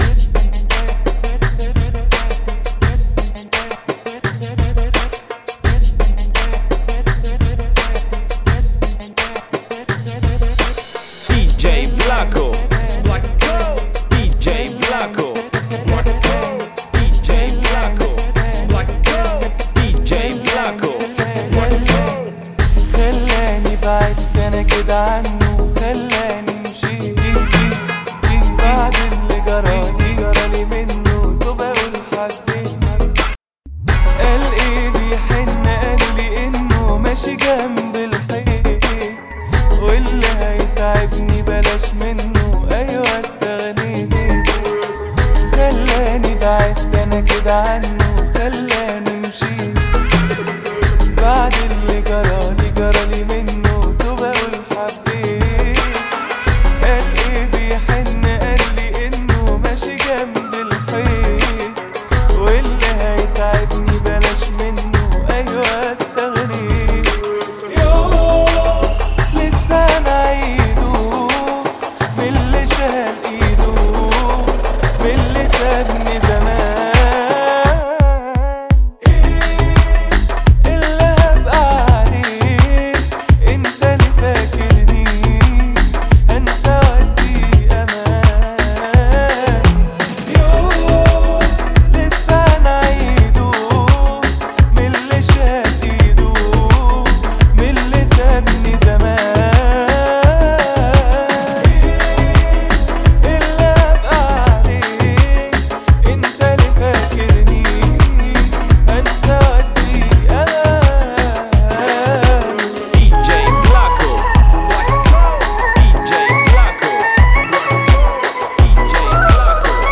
85 bpm